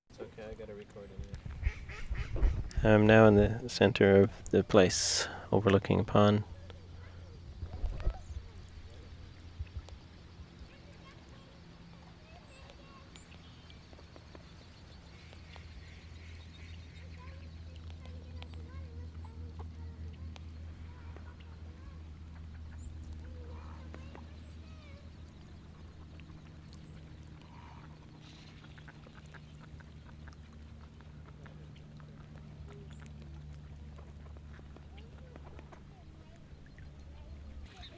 Reifel Bird Sanctuary, centre pond 0:37
4. ducks and child (battery change)